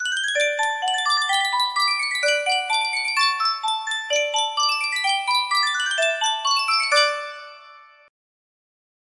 Sankyo Miniature Music Box - Put on Your Old Grey Bonnet DRX music box melody
Full range 60